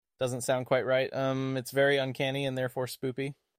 Even when I added an ummm.